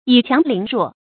倚強凌弱 注音： ㄧˇ ㄑㄧㄤˊ ㄌㄧㄥˊ ㄖㄨㄛˋ 讀音讀法： 意思解釋： 倚：仗恃；凌：欺凌。